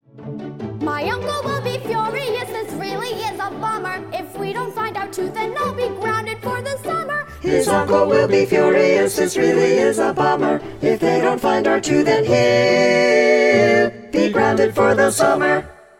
Hear bars 46-56 with all parts sung